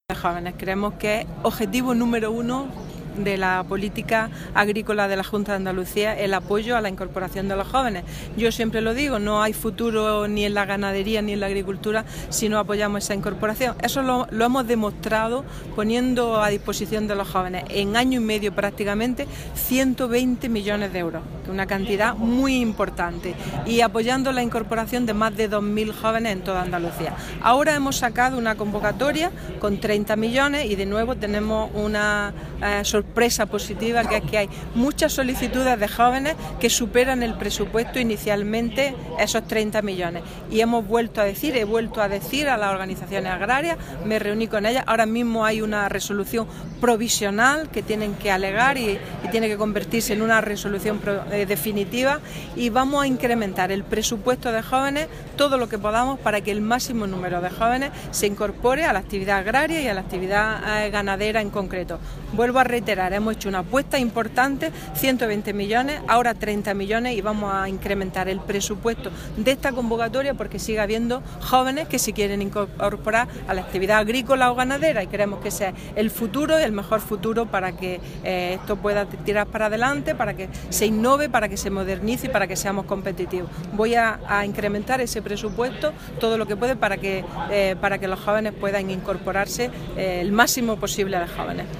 La consejera de Agricultura ha clausurado en Pozoblanco las XXIV Jornadas Técnicas de Covap sobre conservación y patrimonio de este ecosistema
Declaraciones de Carmen Ortiz sobre dehesa